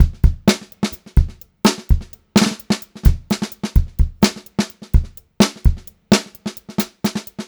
128GRBEAT2-R.wav